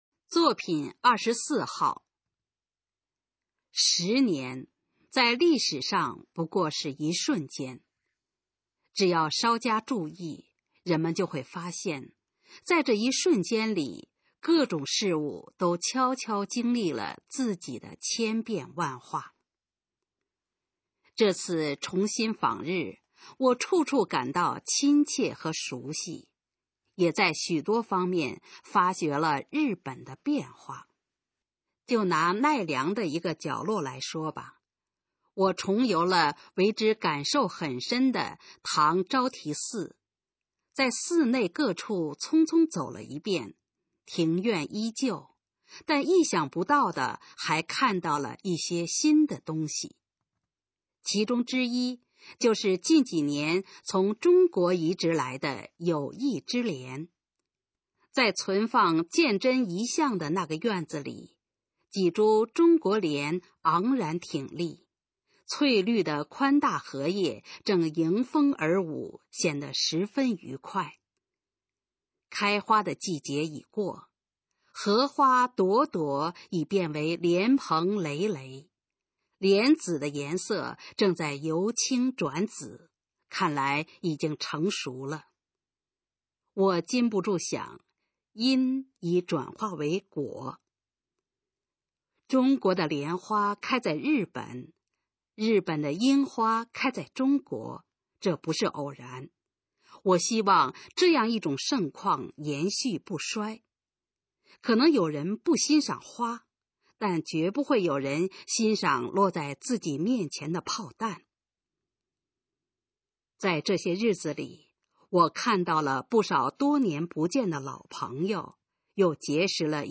《莲花和樱花》示范朗读_水平测试（等级考试）用60篇朗读作品范读